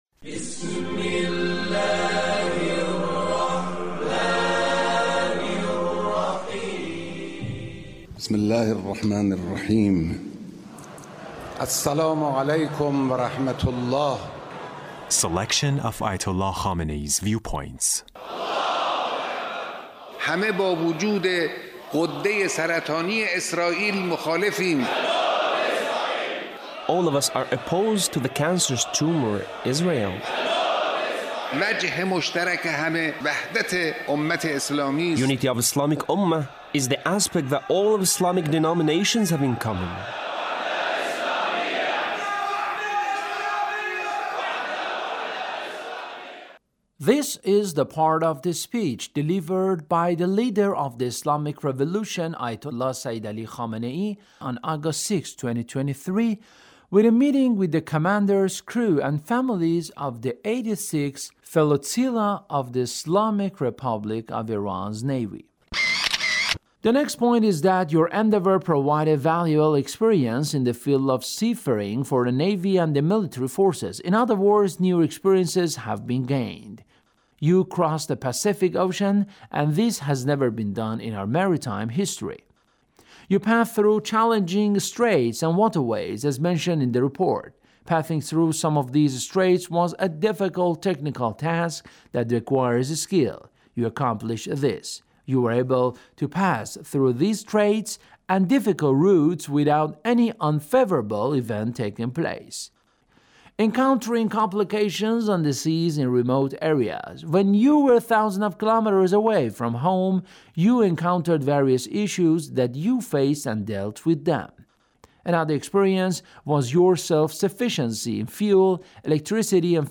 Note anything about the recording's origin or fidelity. Leader's Speech in a meeting with the commanders, crew, and families of the 86th Flotilla of the Islamic Republic of Iran Navy.